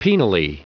Prononciation du mot penally en anglais (fichier audio)
Prononciation du mot : penally